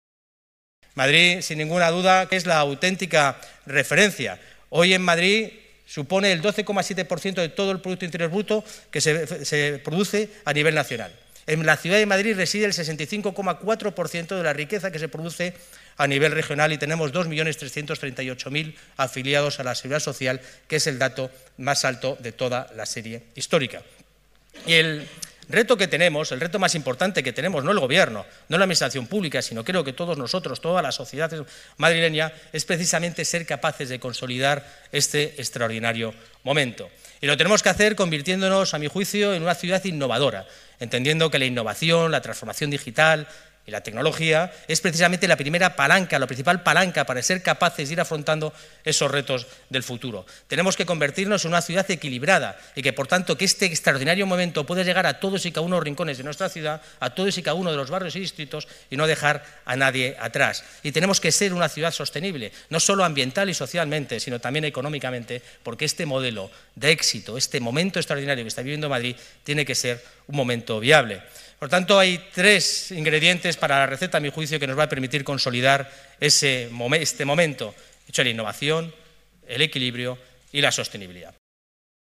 El delegado de Urbanismo, Medio Ambiente y Movilidad ha protagonizado el desayuno informativo organizado por Executive Forum España